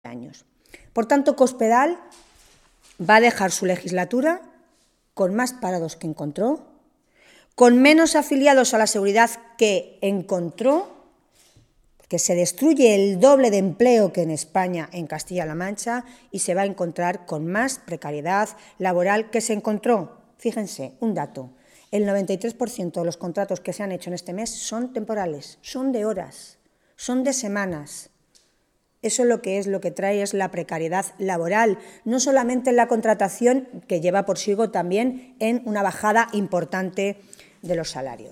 Tolón realizaba estas declaraciones en una rueda de prensa en las que valoró los datos del paro del mes de enero que se han conocido hoy.
Cortes de audio de la rueda de prensa